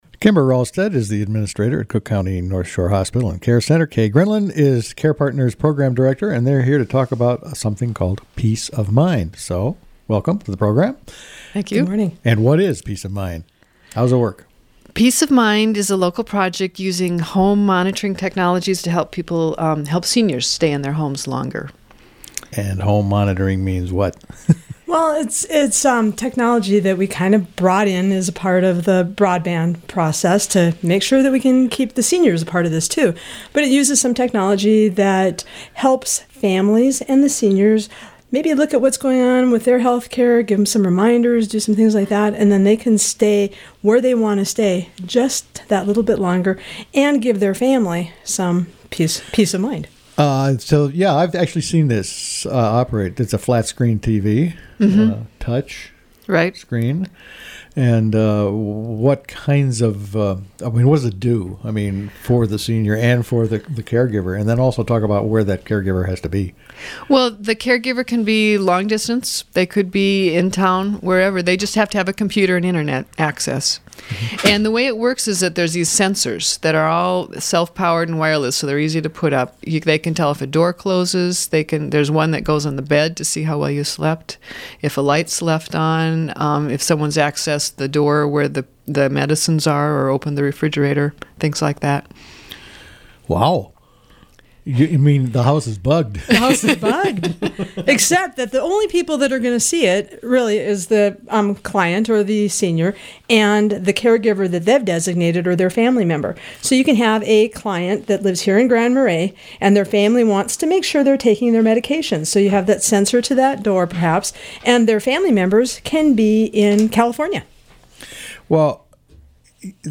interviewed two community health care representatives about “Peace of Mind.”